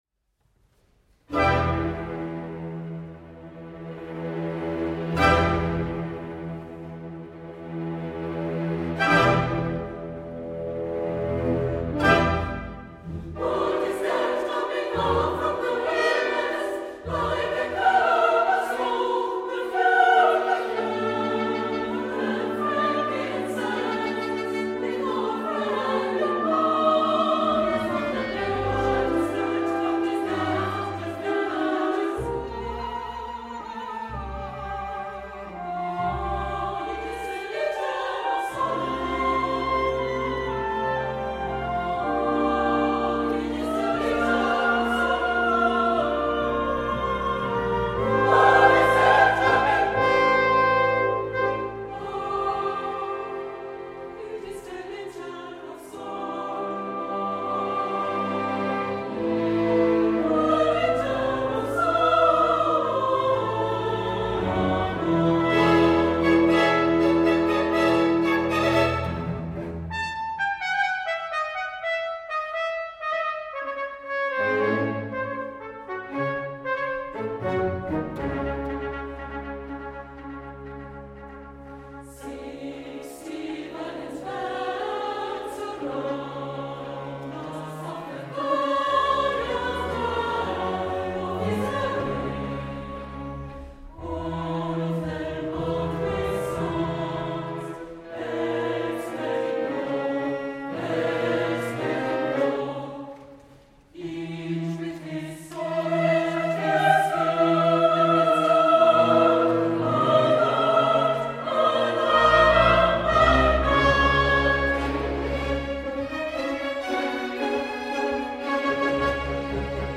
Mezzo Soprano
Tenor
Baritone – Live recording at the Vienna Konzerthaus
The works lasts just over twenty-five minutes and employs, as related above, a number of ethnic colouristic features such as a tribal trill in the sopranos:
The effect is a tight narrative, organic and well structured, employing a sound world that highlights the so-called “oriental” and exotic with mid-twentieth century tonality.